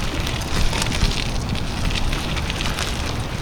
gravel loop 1.aiff